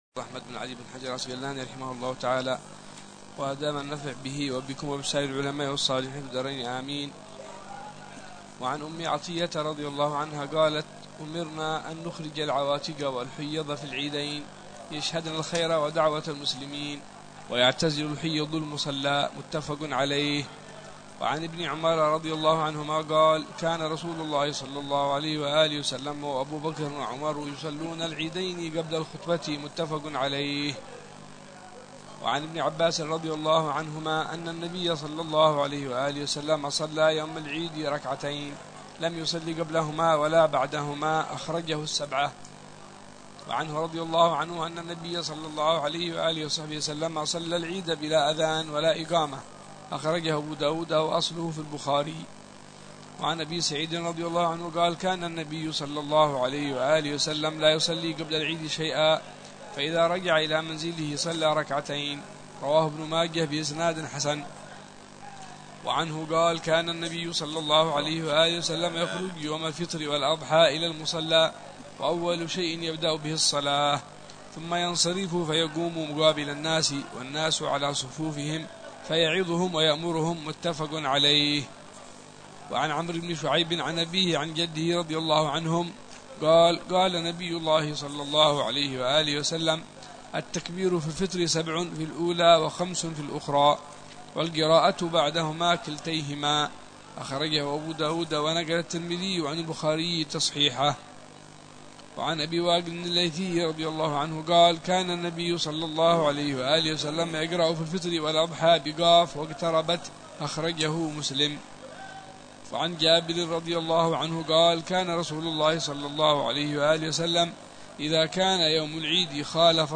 شرح الحبيب عمر بن حفيظ على كتاب بلوغ المرام من أدلة الأحكام للإمام الحافظ أحمد بن علي بن حجر العسقلاني، مختصر يشمل على أصول الأدلة ا